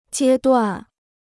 阶段 (jiē duàn) Free Chinese Dictionary